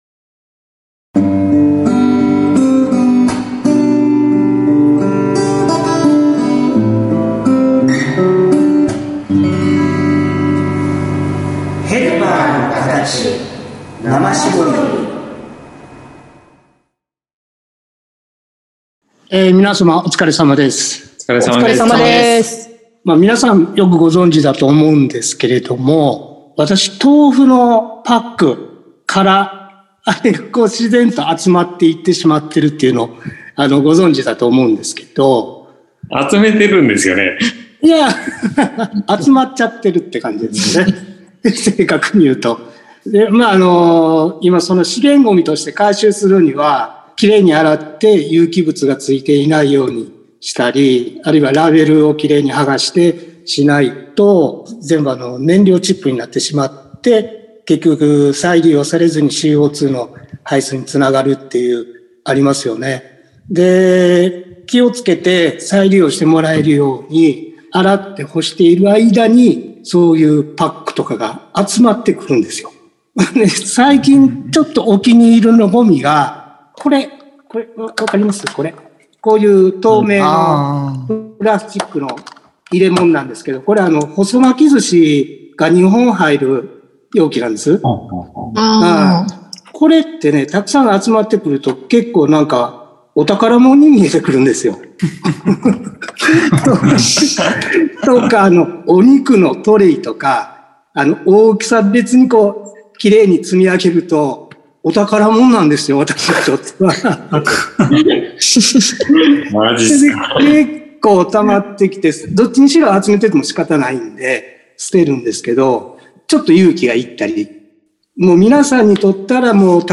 今週と来週はそんなテーマの 談論です。